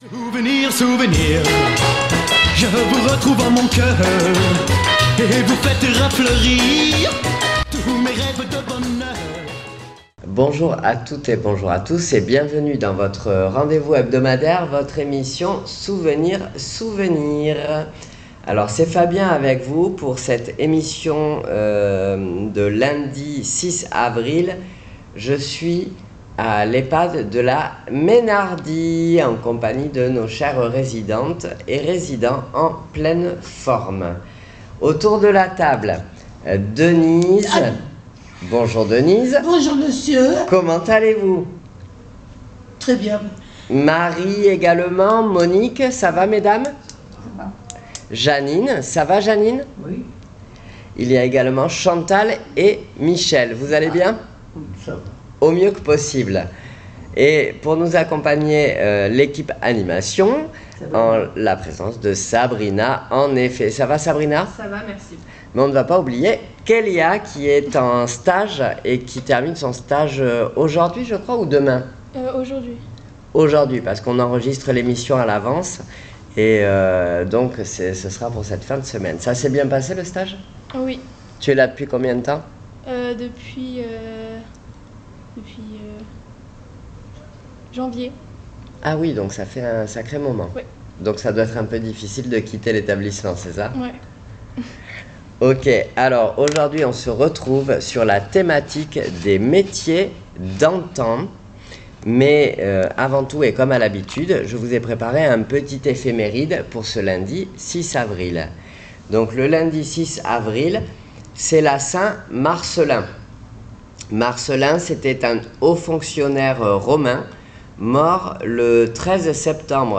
Souvenirs Souvenirs 06.04.26 à l'Ehpad de La Meynardie " Les métiers d'antan "